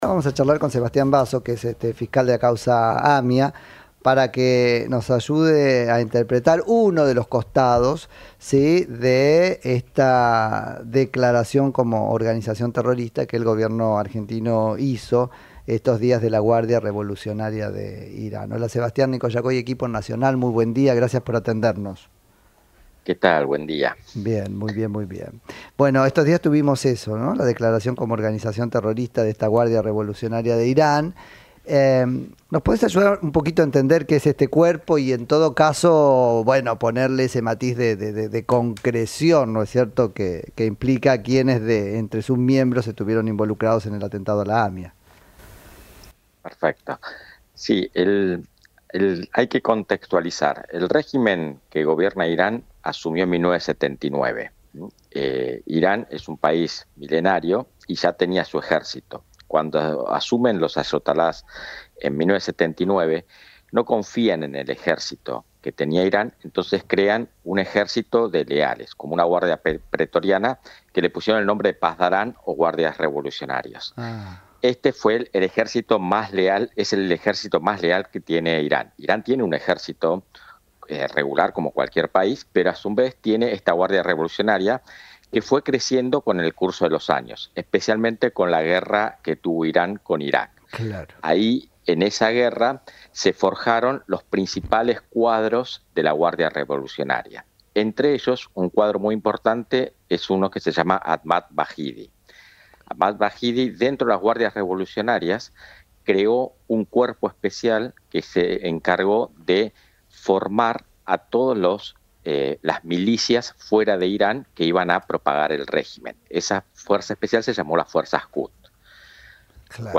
Entrevista al fiscal Sebastián Basso